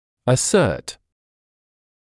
[ə’sɜːt][э’сёːт]утверждать, заявлять